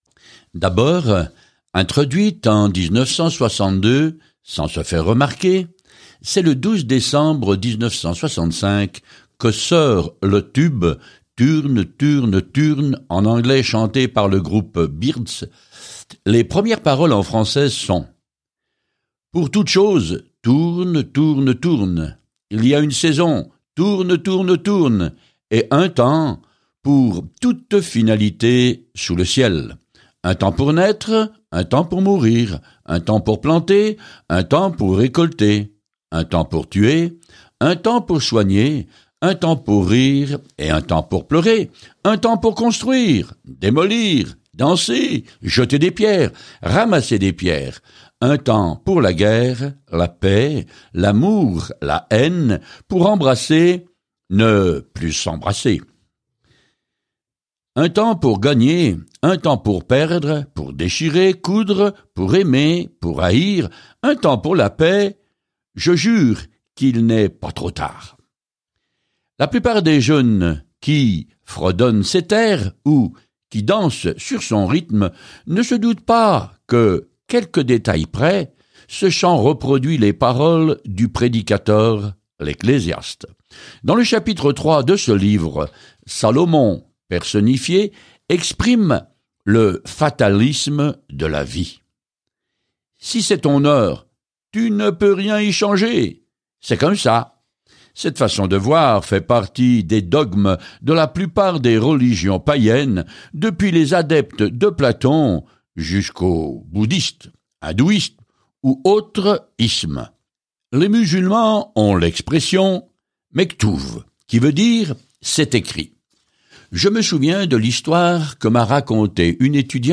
Écritures Ecclésiaste 3 Ecclésiaste 4:1-5 Jour 4 Commencer ce plan Jour 6 À propos de ce plan L’Ecclésiaste est une autobiographie dramatique de la vie de Salomon alors qu’il essayait d’être heureux sans Dieu. En voyageant quotidiennement à travers l’Ecclésiaste, vous écoutez l’étude audio et lisez des versets sélectionnés de la parole de Dieu.